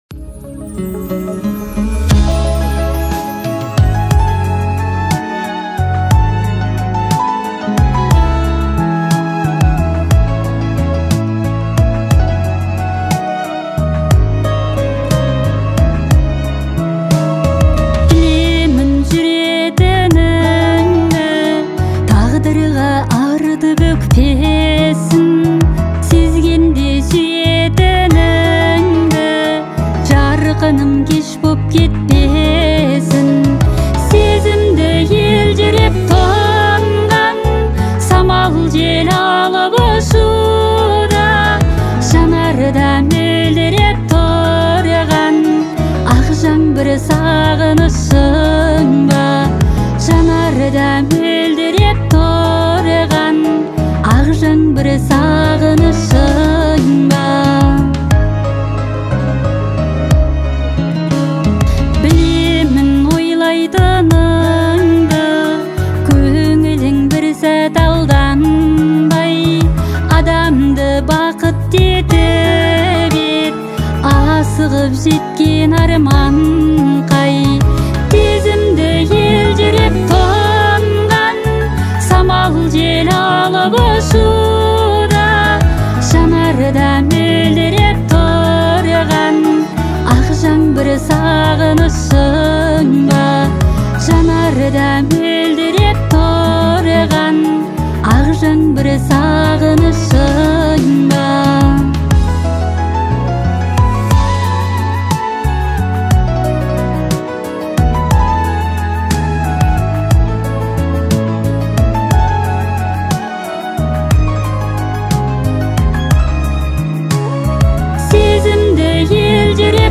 Жанр: Узбекские песни Слушали